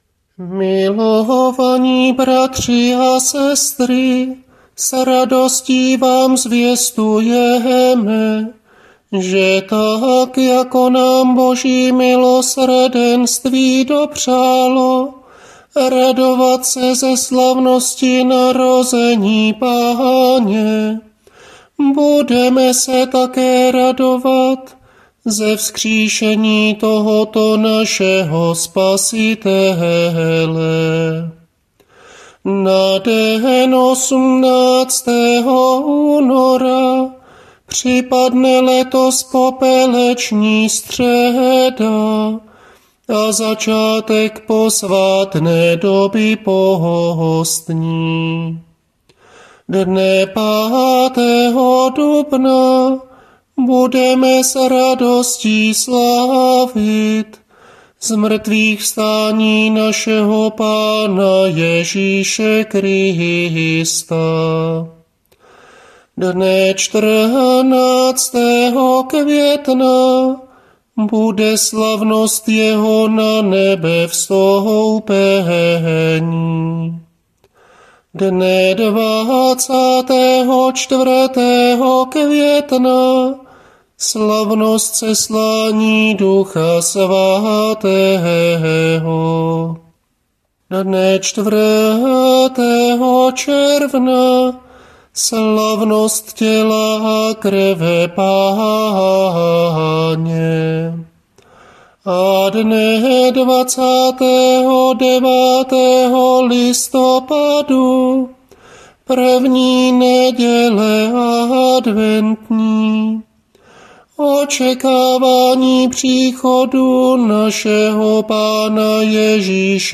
Nápěvy ohlášení